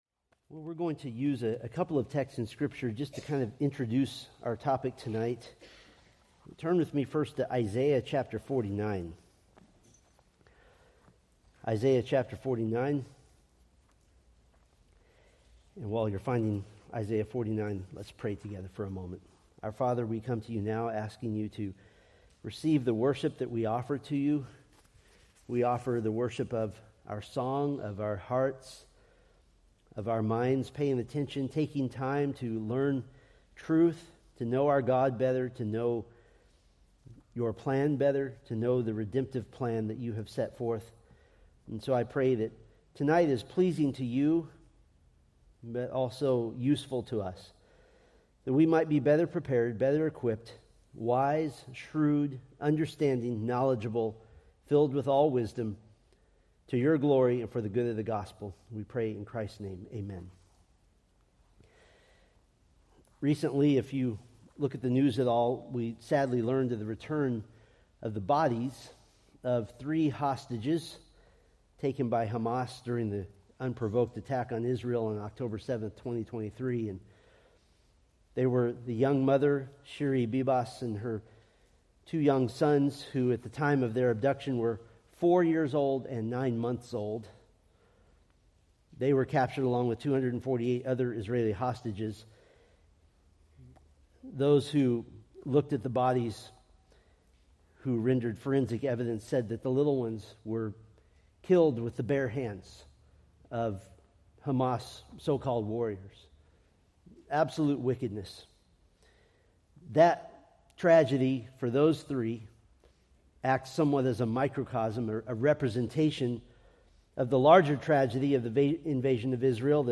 Preached March 2, 2025 from Selected Scriptures